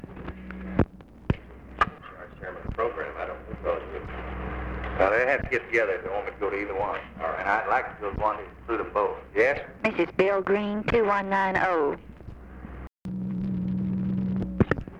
OFFICE CONVERSATION, December 09, 1963
Secret White House Tapes | Lyndon B. Johnson Presidency